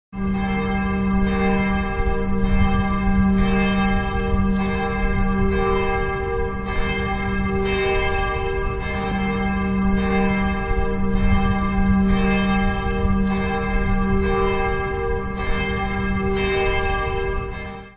Deutlich sichtbar in den horizontal gegliederten romanischen Bauformen ist schon der Beginn der gotischen Bauweise. Glockenspiel von Gro� St. Martin